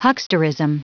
Prononciation du mot hucksterism en anglais (fichier audio)
Prononciation du mot : hucksterism